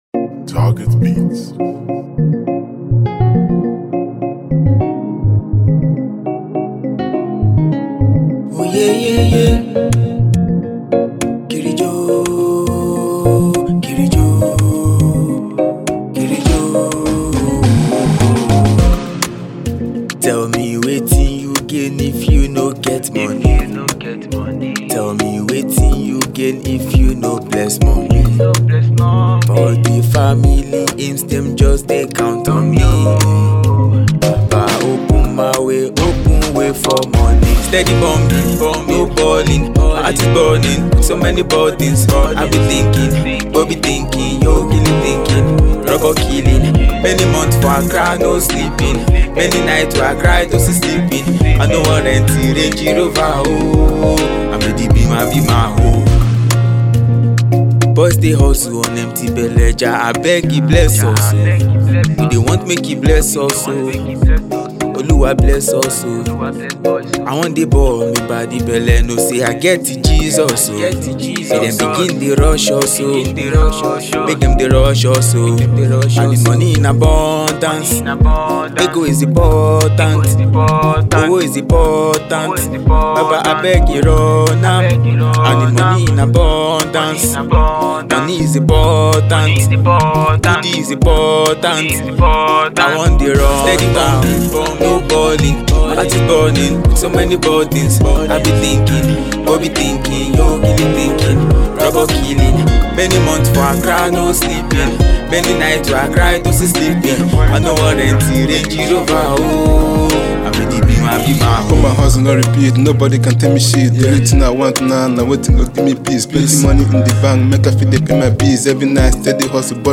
Talented music singer, and rapper
Afrobeat